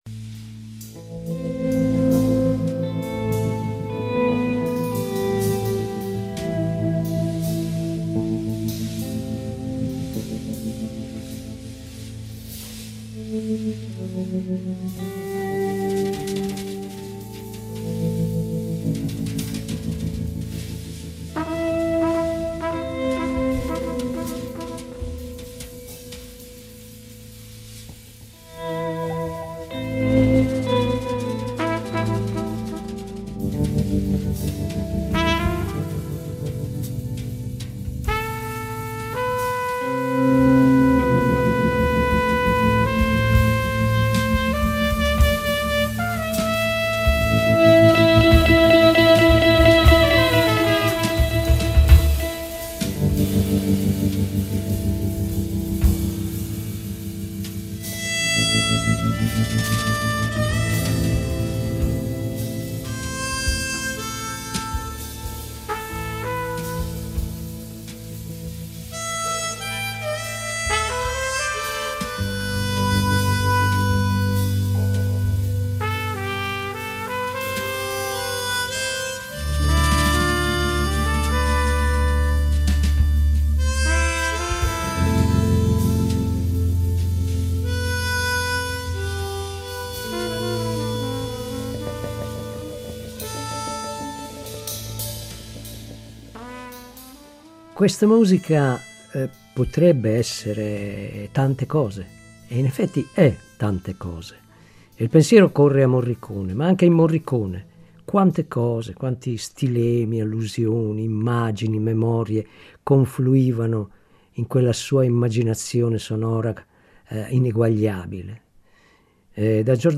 La Recensione
Jazz, folklore, psichedelia, lounge music, spaghetti western, noir, hard-boiled eccetera.